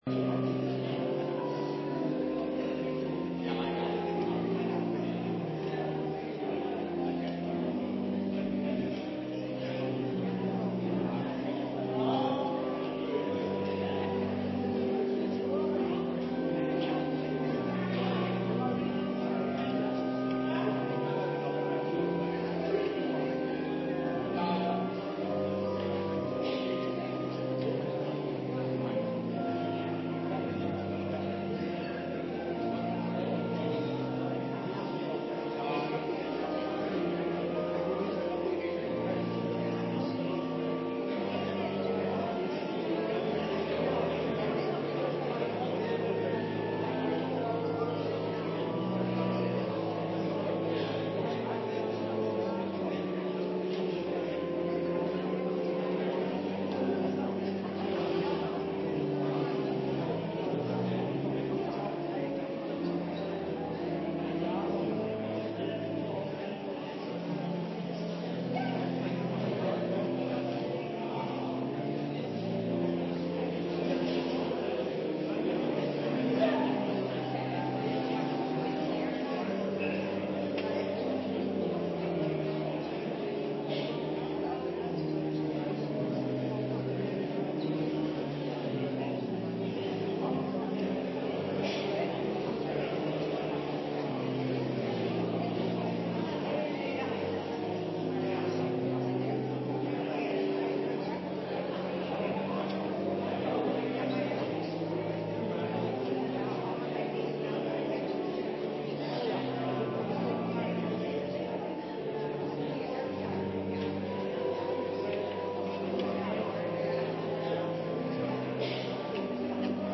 Opnames uit de Ontmoetingskerk.